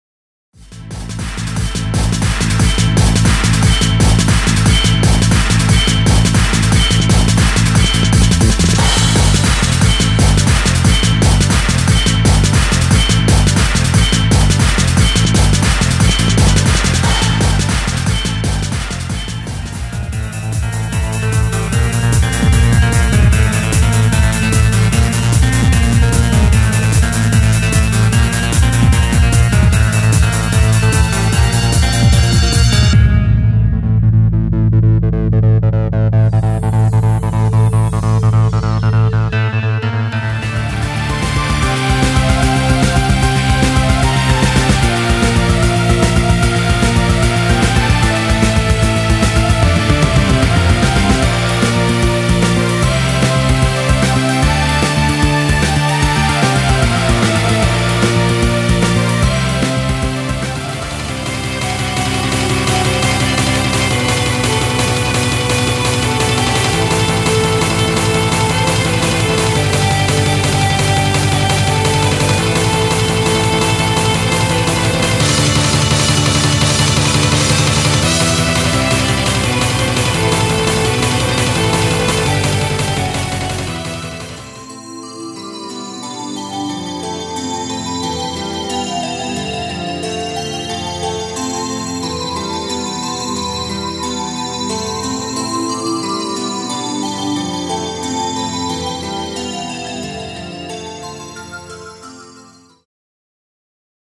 内容は「中ボス戦BGM→中ボス戦BGMその２→道中BGM→ボスBGM→イベントBGM」